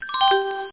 CHIMES.mp3